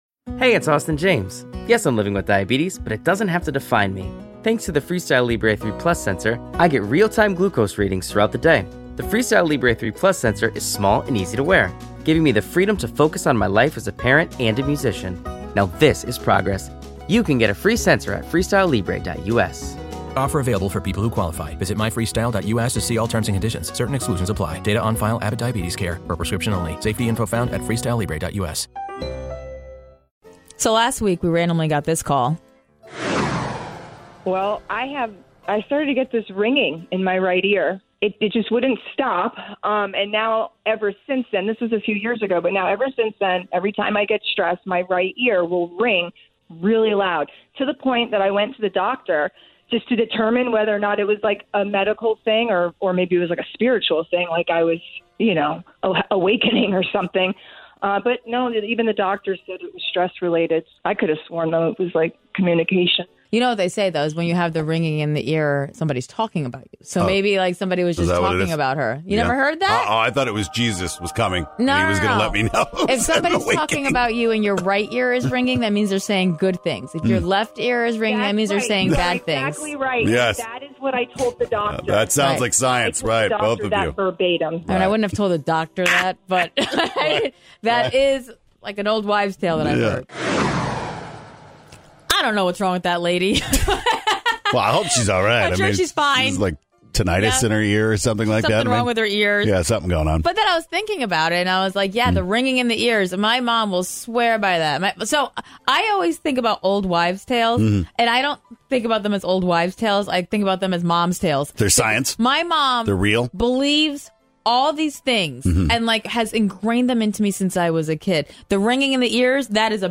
After getting a call last week, we realized that most people really believe old wives' tales! Which one did the callers miss?!